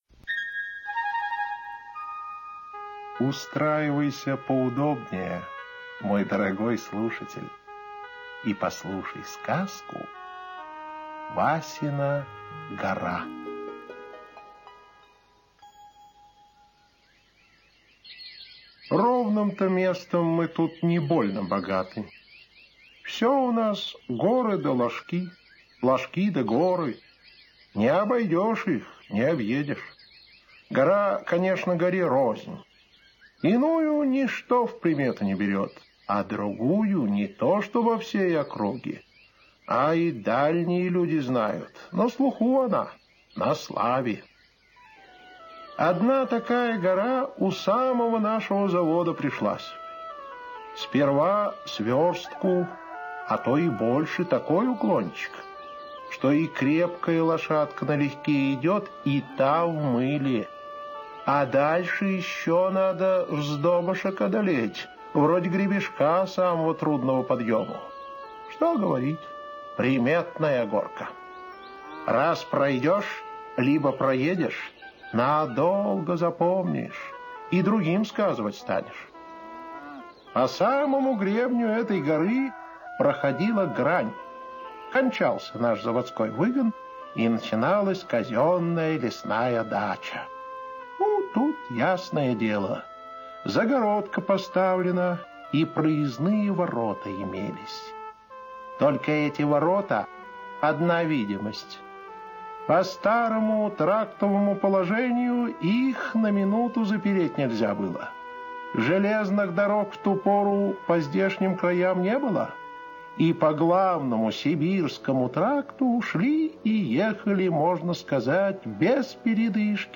Васина гора - аудиосказка Павла Бажова - слушать онлайн